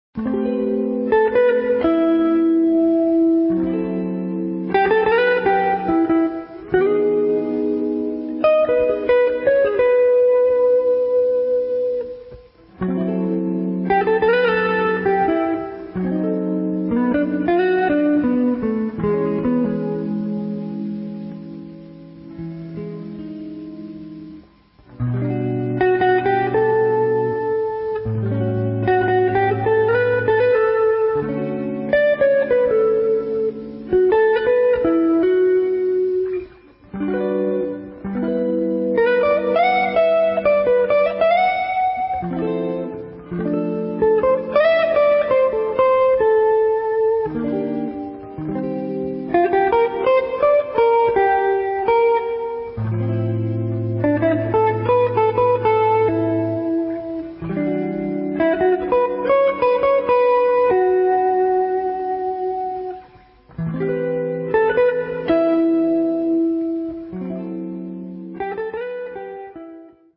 Two instrumental suites.